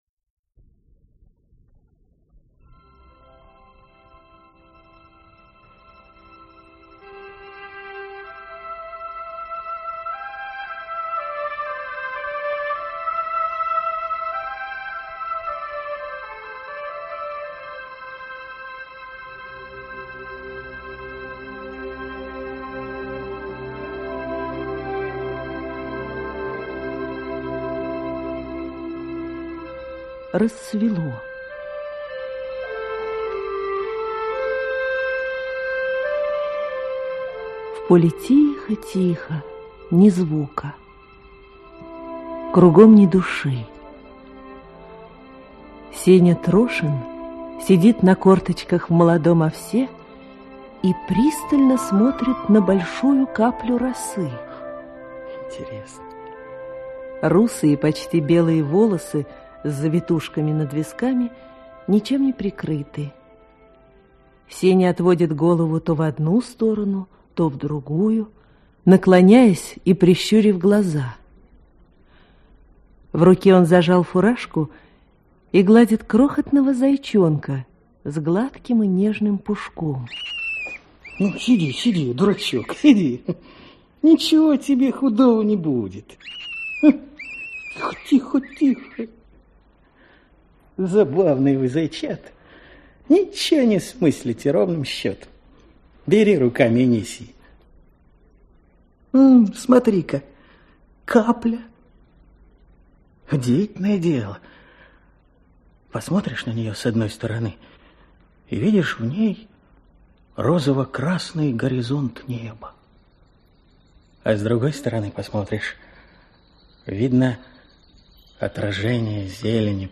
Аудиокнига У крутого яра | Библиотека аудиокниг